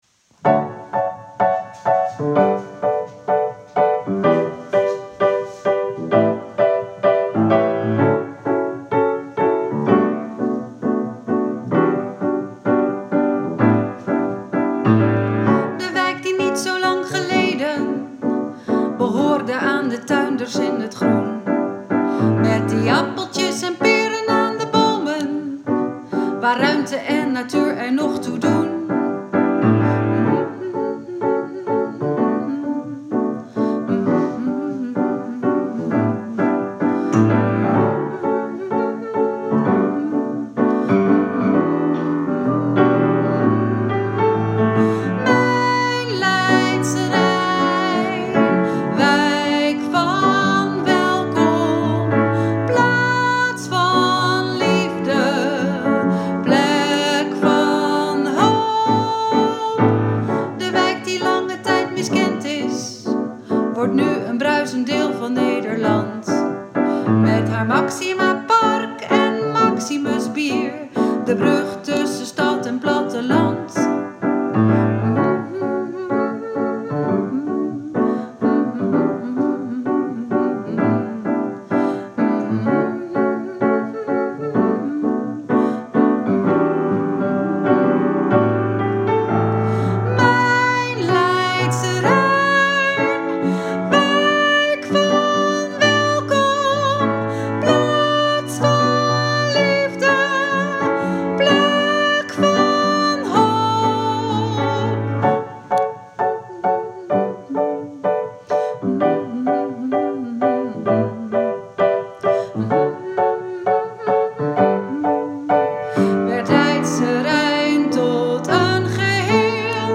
Mijn Leidsche Rijn - Tenor
Tenor-mijn-Leidsche-rijn.m4a